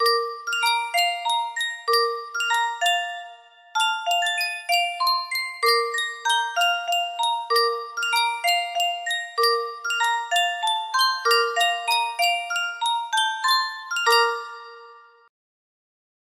Sankyo Music Box - Rock-a-Bye Baby R music box melody
Full range 60